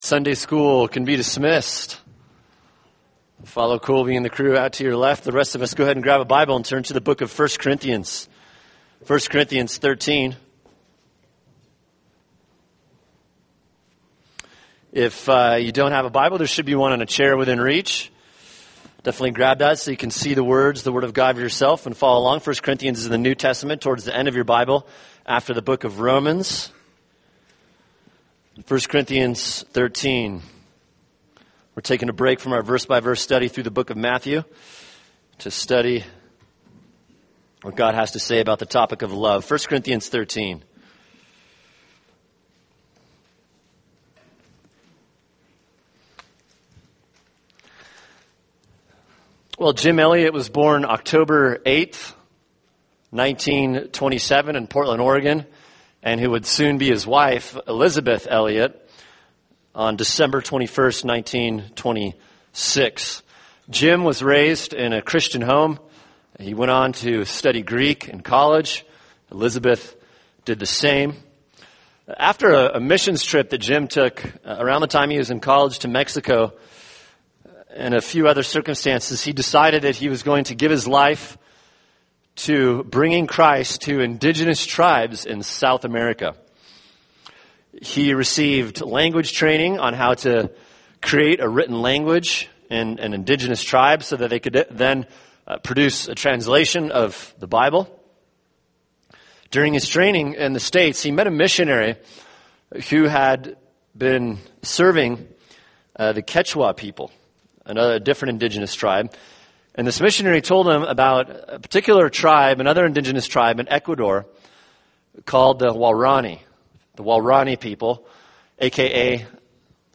[sermon] 1 Corinthians 13:1-7 – Love (part 4) | Cornerstone Church - Jackson Hole